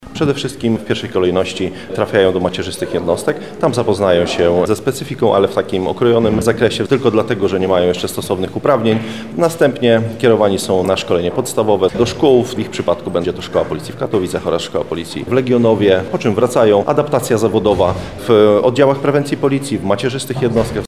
O tym jakie są następne kroki w karierze nowych funkcjonariuszy policji powiedział Komendant Wojewódzki Policji w Lublinie, młodszy inspektor Tomasz Gil.